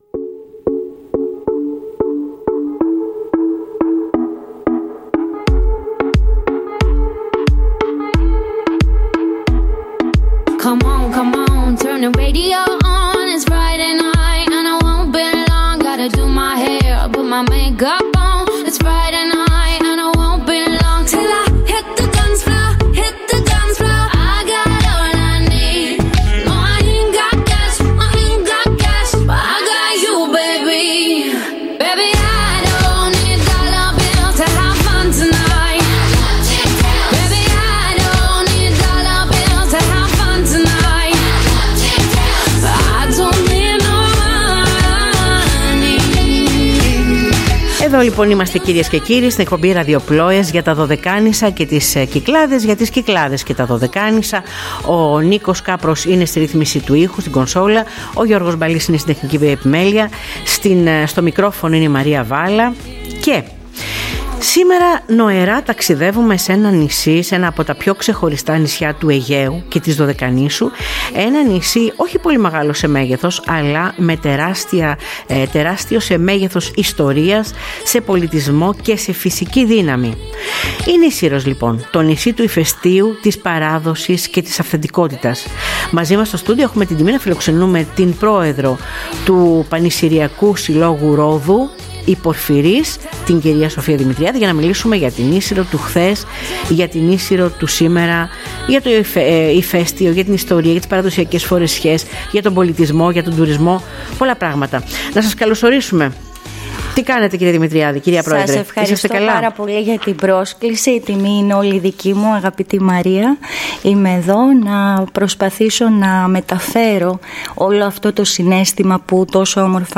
σε συνέντευξή της στην εκπομπή ΡΑΔΙΟΠΛΟΕΣ